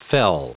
Prononciation du mot fell en anglais (fichier audio)
Prononciation du mot : fell